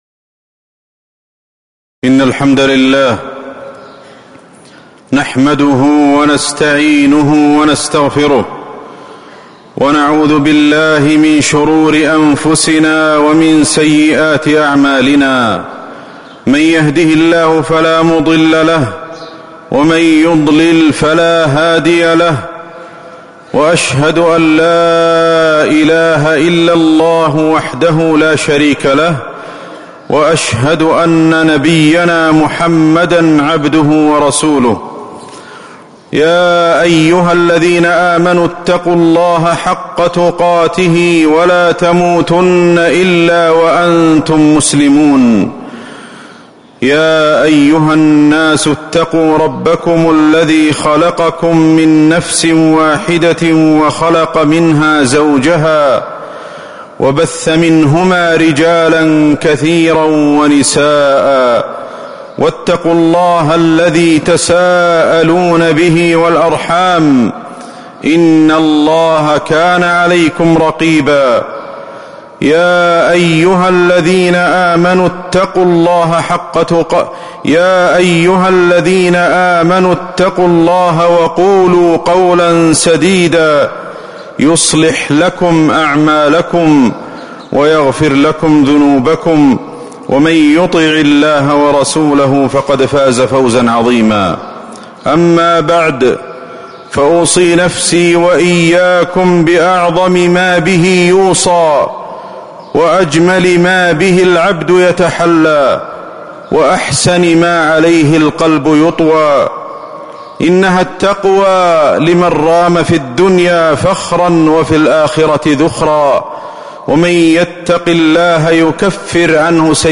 تاريخ النشر ٧ رمضان ١٤٤٦ هـ المكان: المسجد النبوي الشيخ: فضيلة الشيخ أحمد بن علي الحذيفي فضيلة الشيخ أحمد بن علي الحذيفي فضائل رمضان